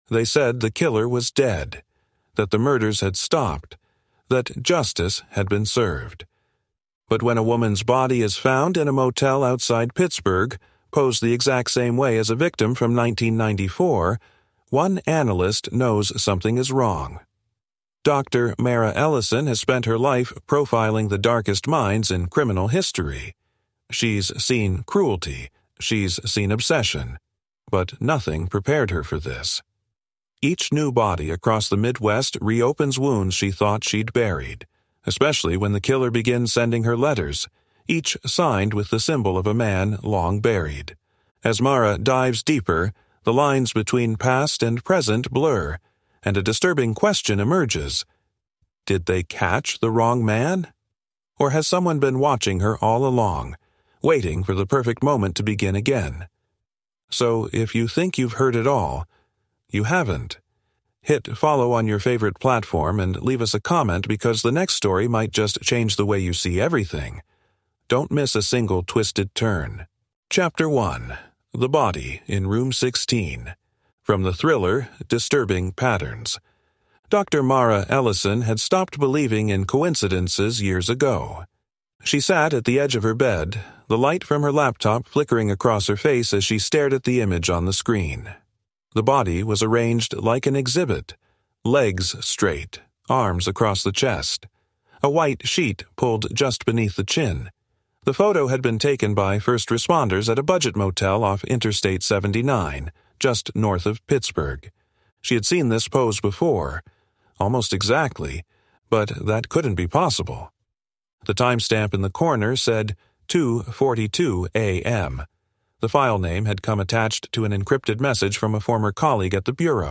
Blending psychological suspense with methodical pacing, Disturbing Patterns is a cinematic thriller that explores how rituals are born, how violence echoes through generations, and how silence can be deadly. Inspired by real-world behavioral profiling and cult psychology, this audio drama will leave you questioning every pattern